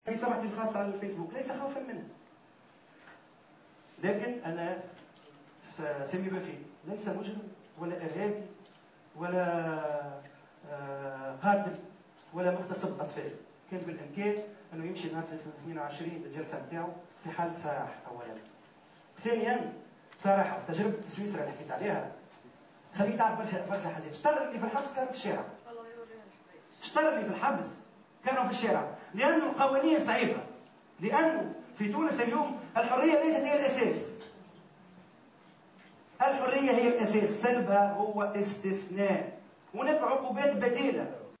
عبّر معز بن غربية اليوم خلال الندوة الصحفية التي عقدها للإعلان عن انطلاق بث قناة التاسعة عن استغرابه من ايقاف الإعلامي سمير الوافي وإيداعه السجن.